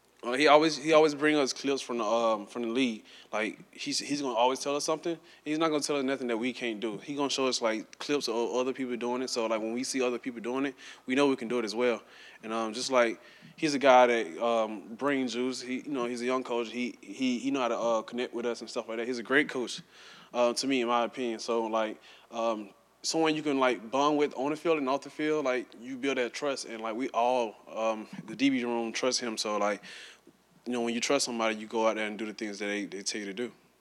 In a news conference Wednesday, the senior spoke at length about the Gators getting back on track following last week’s blowout loss at Texas.